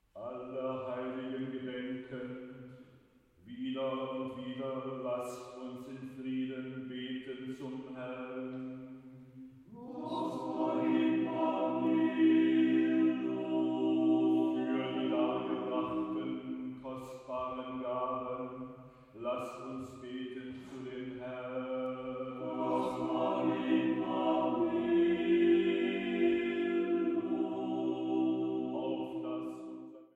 Religiöse Vokalwerke aus Armenien